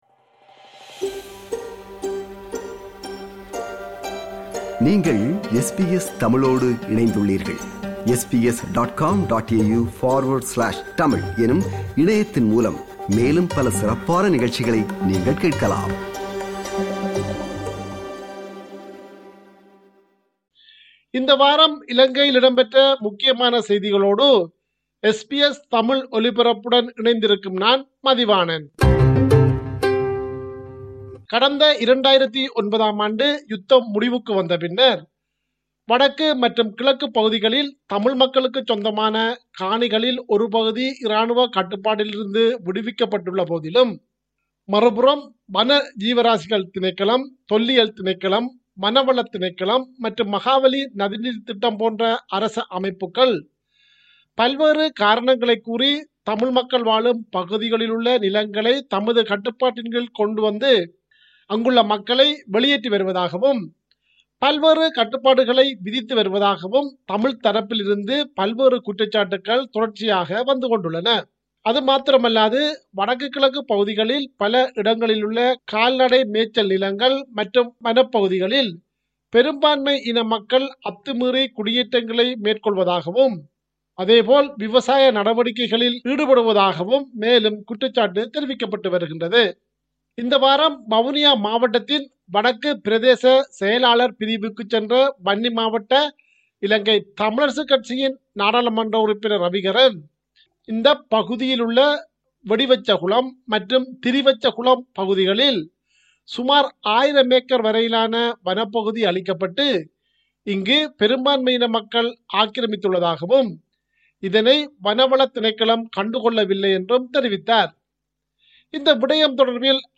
“செய்தியின் பின்னணி” நிகழ்ச்சிக்காக விவரணமொன்றை முன்வைக்கிறார்